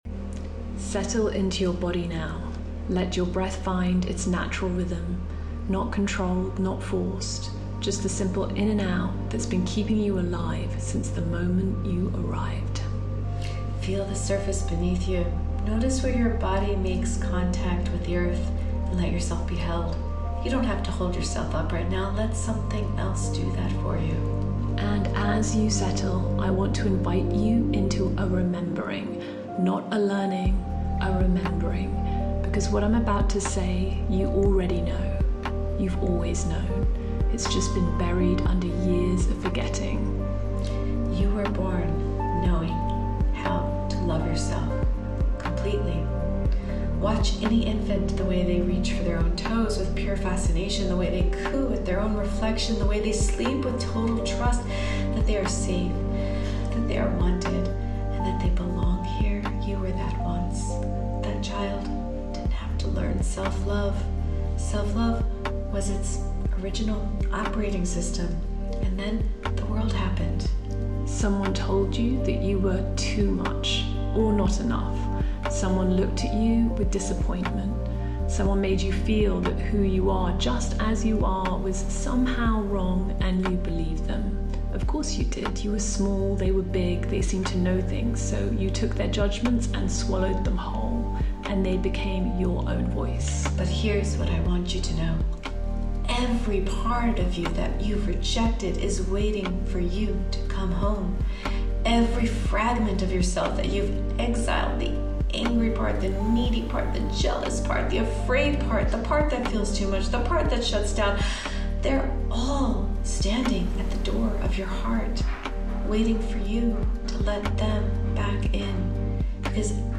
A 5-minute guided meditation to transmute anger into healing
meditation-self-love.mp3